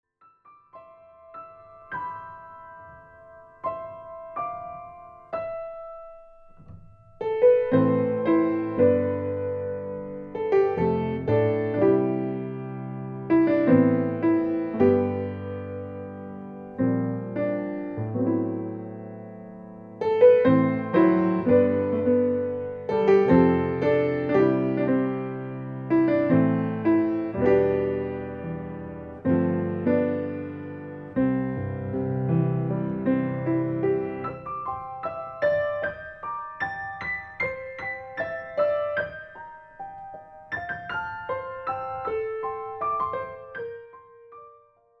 This music is wonderful for relaxation and meditation.